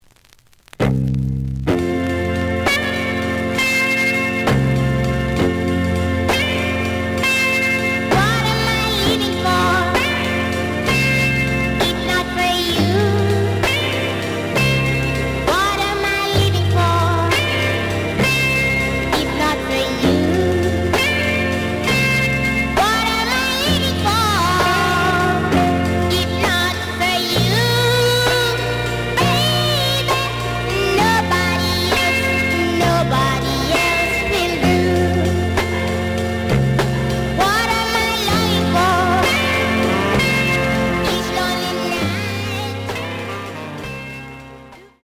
The audio sample is recorded from the actual item.
●Genre: Ska